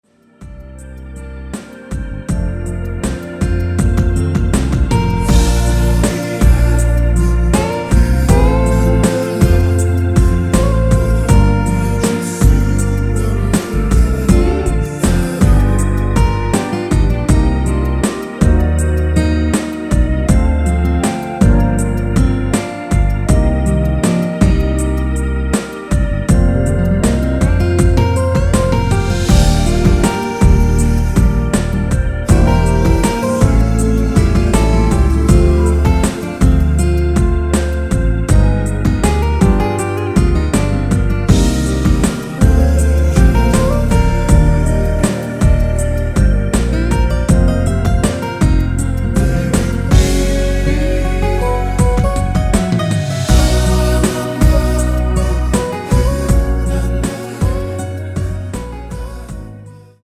원키에서(-2)내린 멜로디와 코러스 포함된 MR입니다.(미리듣기 확인)
Bb
앞부분30초, 뒷부분30초씩 편집해서 올려 드리고 있습니다.
중간에 음이 끈어지고 다시 나오는 이유는